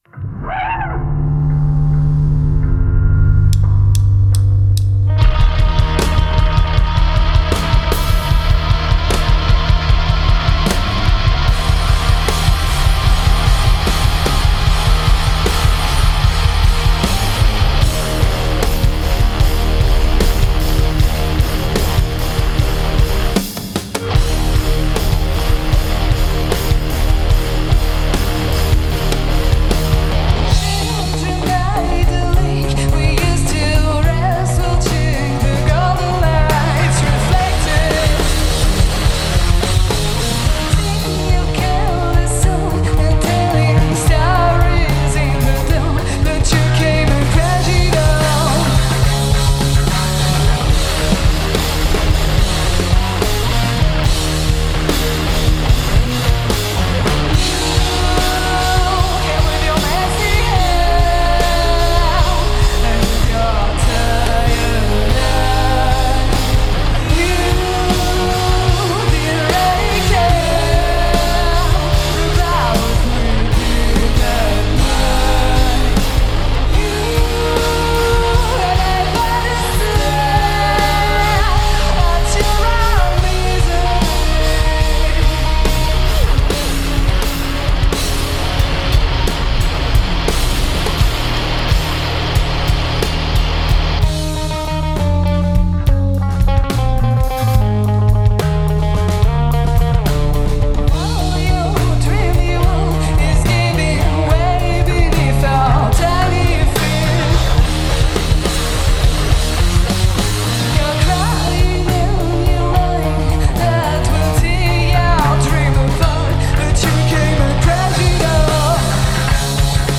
deceptively riotous.
Parisian trio
They are, above all a power trio.
who plays bass and sings
A short set at just over 9 minutes – check them out.